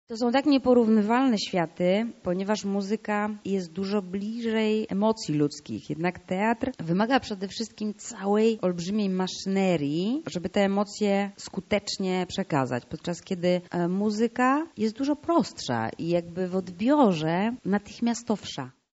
Jednak tym razem artystka nie przyjechała śpiewać lecz wzięła udział w dyskusji na temat przyszłości szeroko-pojętej kultury w Polsce.